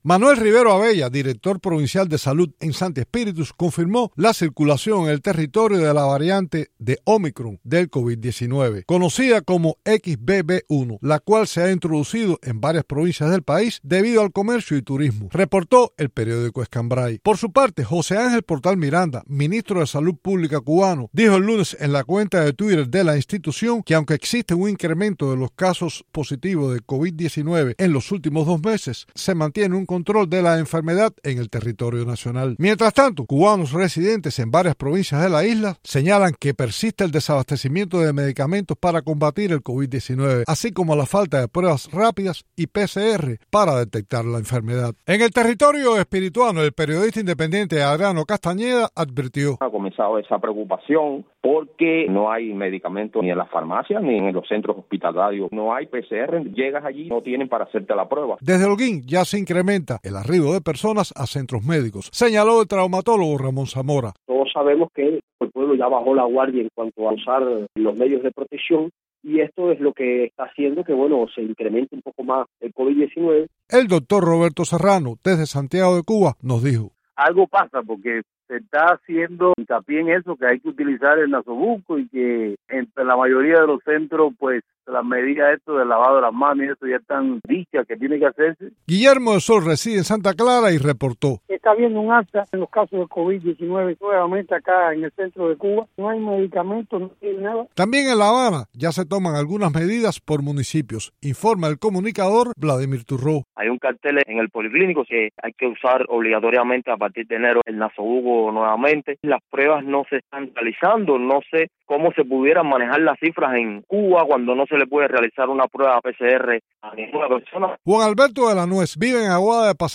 Mientras tanto, cubanos residentes en varias provincias, entrevistados por Radio Televisión Martí, señalaron que persiste el desabastecimiento de alimentos para combatir el Covid-19, así como el faltante de pruebas rápidas y PCR para detectar la enfermedad.